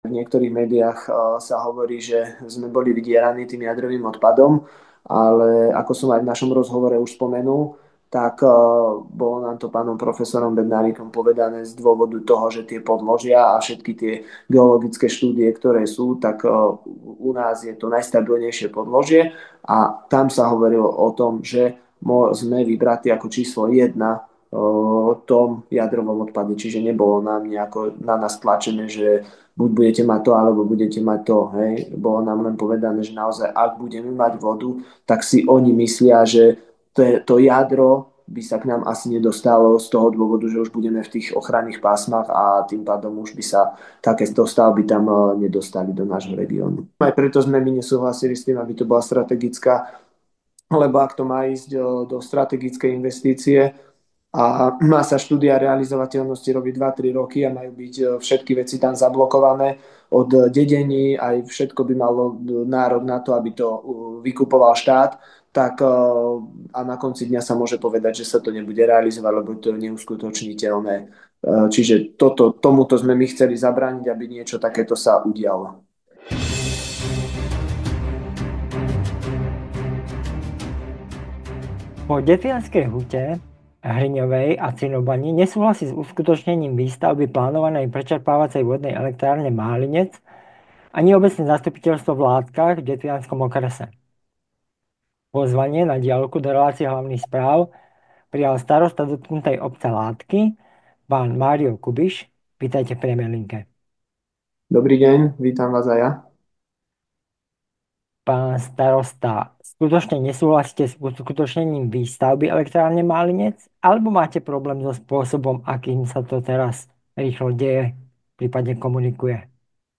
Pozvanie na diaľku do relácie Hlavných Správ prijal starosta dotknutej obce Látky, pán Mário Kubiš.